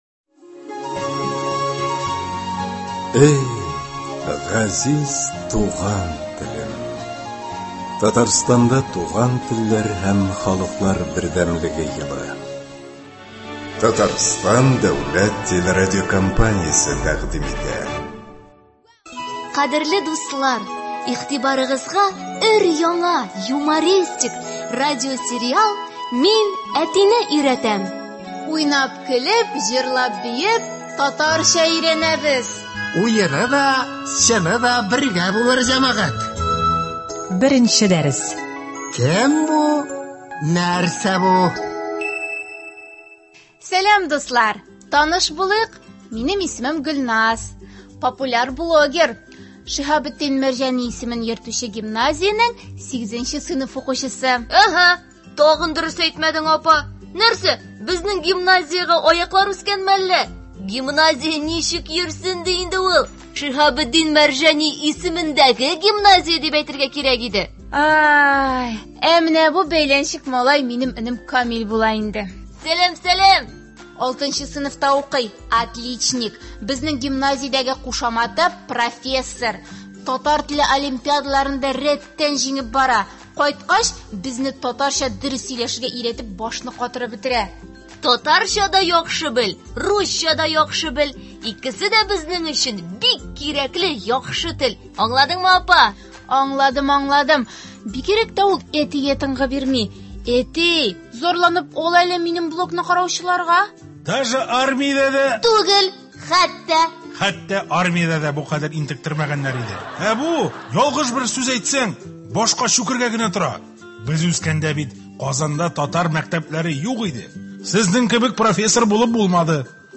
Ул – “Мин әтине өйрәтәм” дип исемләнгән радиосериал. Кыска метражлы әлеге радиоспектакльләрдә туган телебезне бозып сөйләшү көлке бер хәл итеп күрсәтелә һәм сөйләмебездәге хата-кимчелекләрдән арыну юллары бәян ителә.